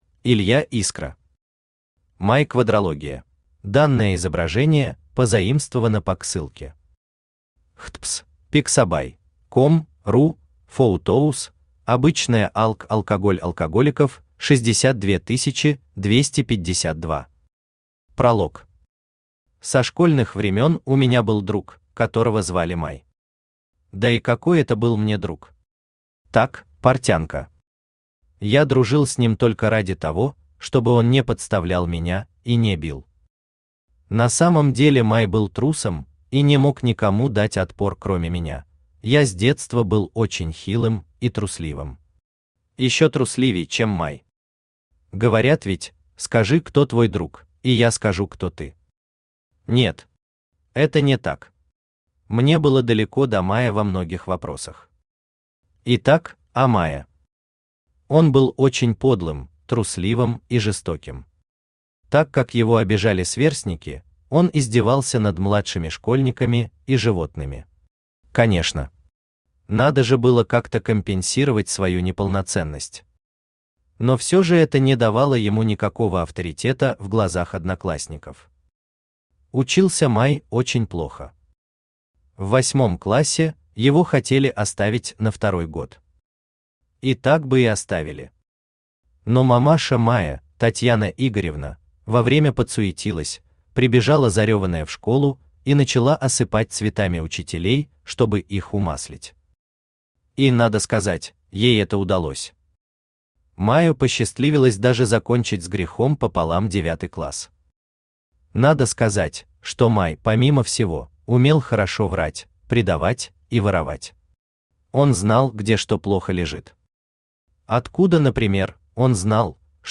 Aудиокнига Май Квадрология Автор Илья Искра Читает аудиокнигу Авточтец ЛитРес.